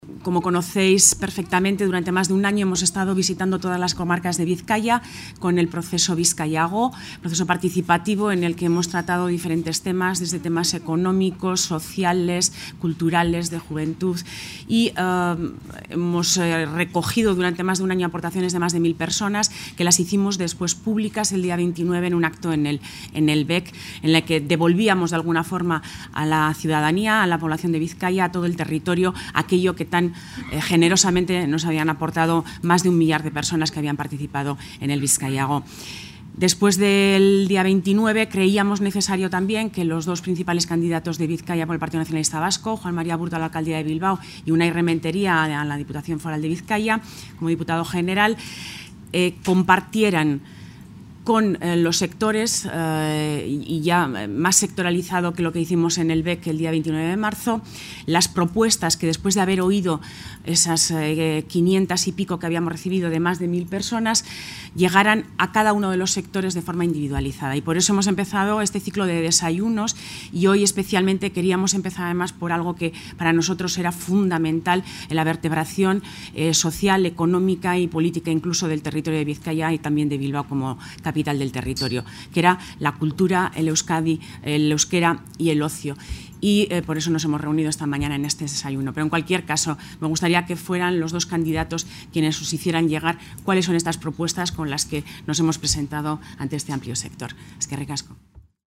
• Itxaso Atutxa en el encuentro sectorial sobre cultura 22/04/2015